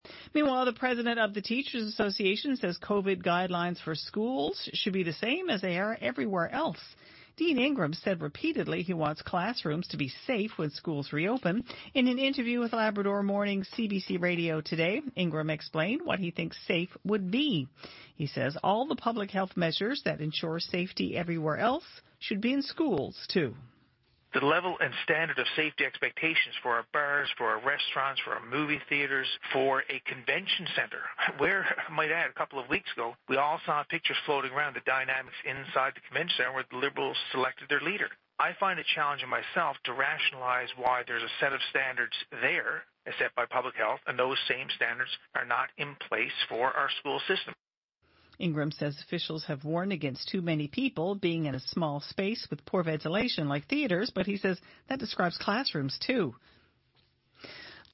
Media Interview - CBC 5pm News - Aug 13, 2020